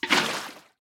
Minecraft Version Minecraft Version snapshot Latest Release | Latest Snapshot snapshot / assets / minecraft / sounds / item / bucket / empty1.ogg Compare With Compare With Latest Release | Latest Snapshot